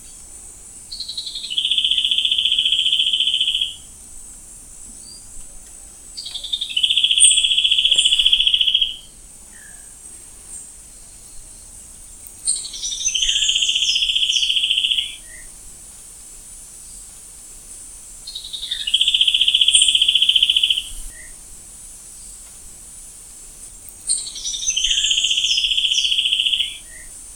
Bico-assovelado (Ramphocaenus melanurus)
Nome em Inglês: Trilling Gnatwren
Detalhada localização: Parque Municipal de Maceió
Condição: Selvagem
Certeza: Fotografado, Gravado Vocal